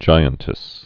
(jīən-tĭs)